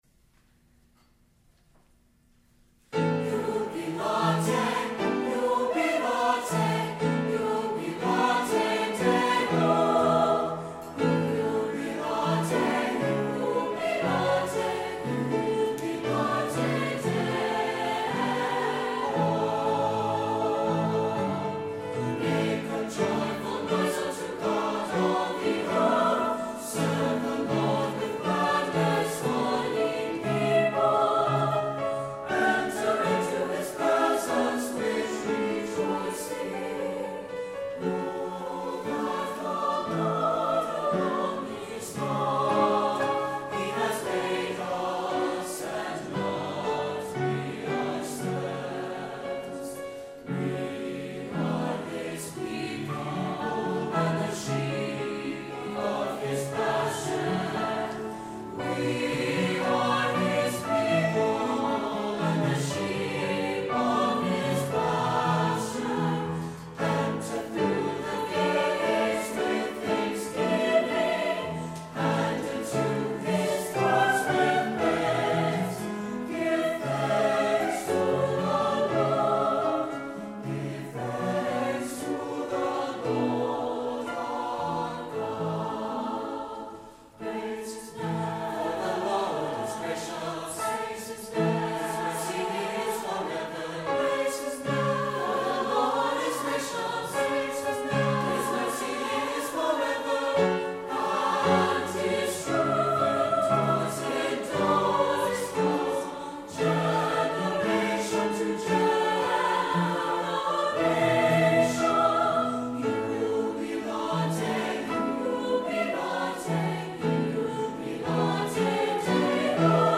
Accompaniment:      With Piano
Music Category:      Choral